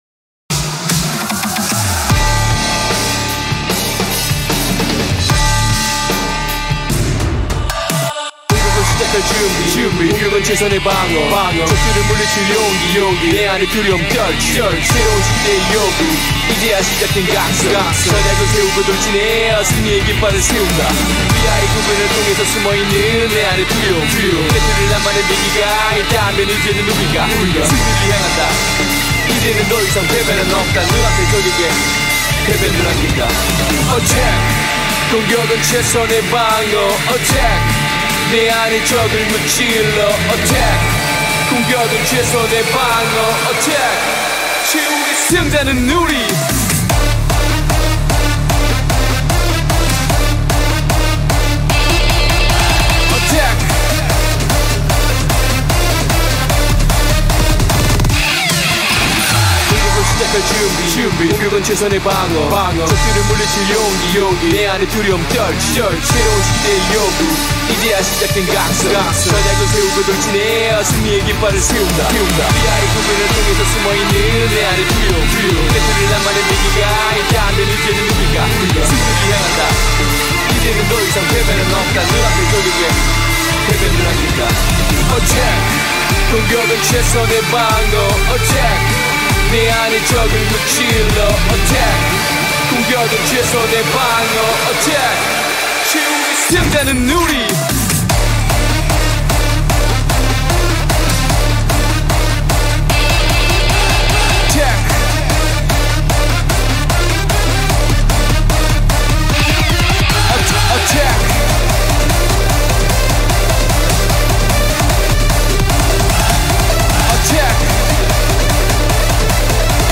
BPM150-300
Comments[HARDSTYLE]